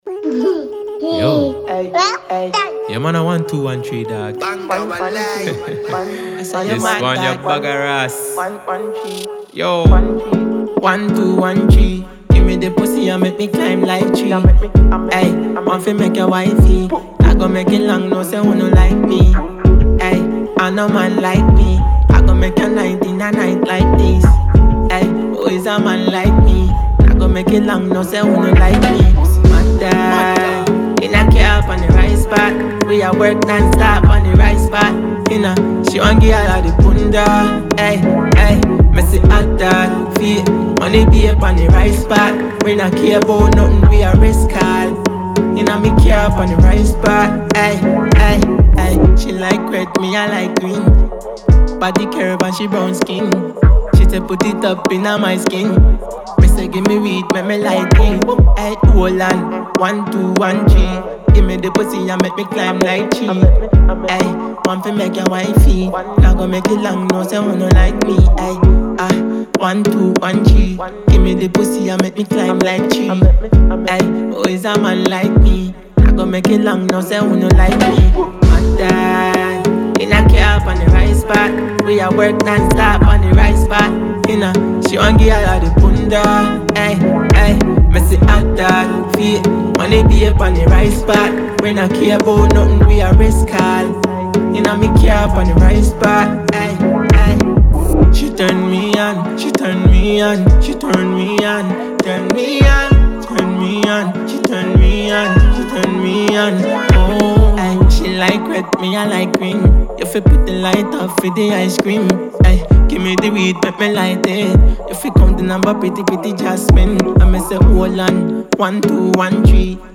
a Ghanaian female Disc Jockey
dancehall artist